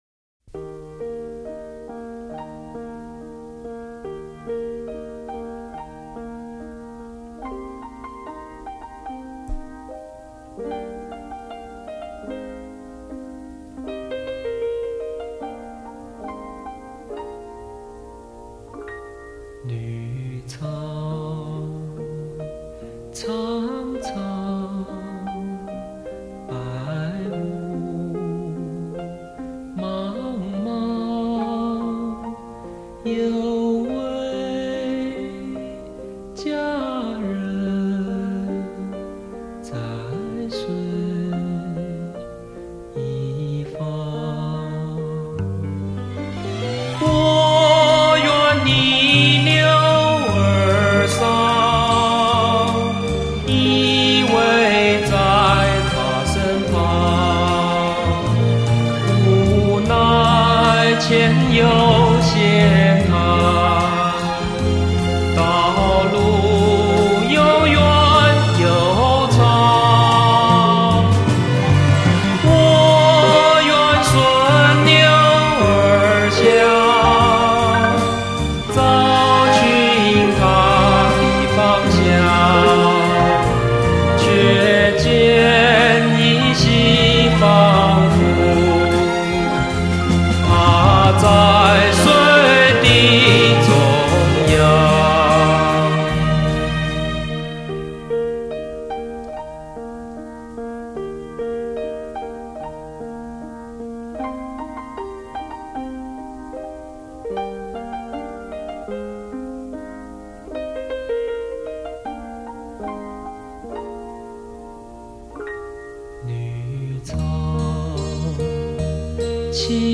低音质试听